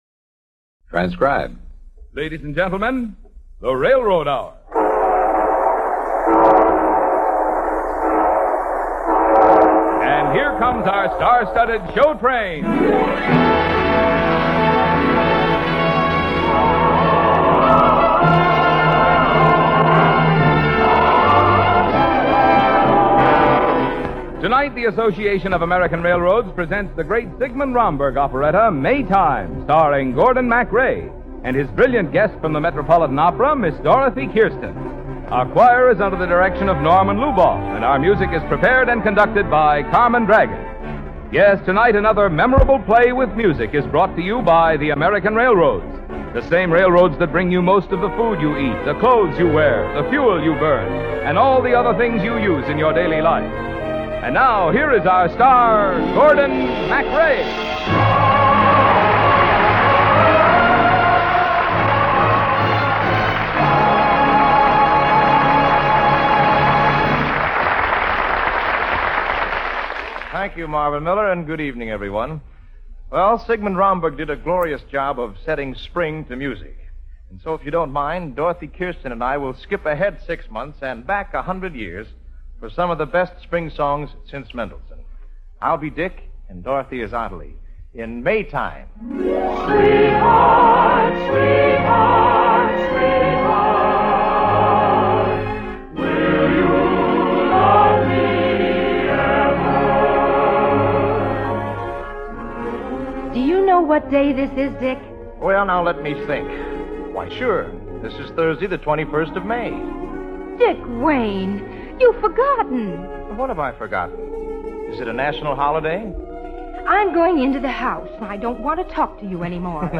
musical dramas and comedies
hosted each episode and played the leading male roles